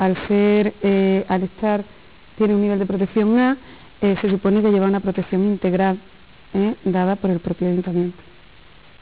A finales de 1997 se organizó un debate televisivo en Onda Giralda Televisión, en el cual se mostraron las diferentes posturas existentes sobre el Puente de Alfonso XIII.
- Pilar Gómez Casero, Delegada provinciial de Cultura
Pilar Gómez Casero